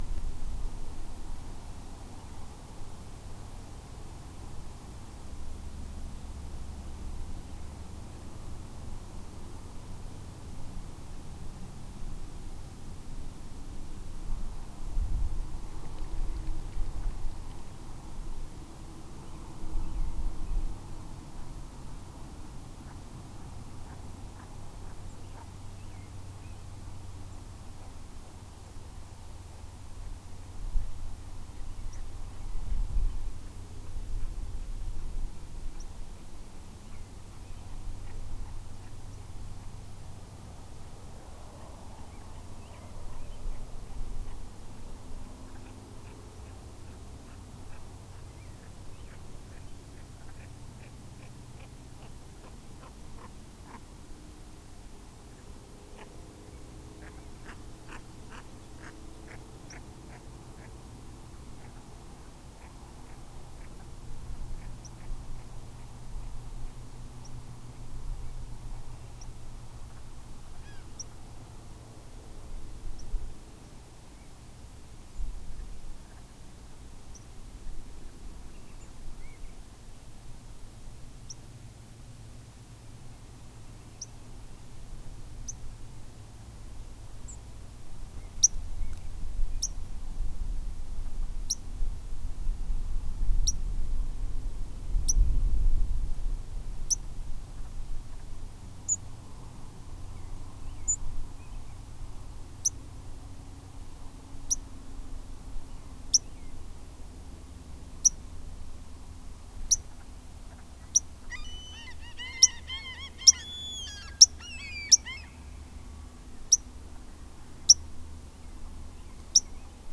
Late night sounds at this site in the Kenai National Wildlife Refuge on May 19, 2010